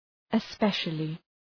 Shkrimi fonetik {ı’speʃəlı}
especially.mp3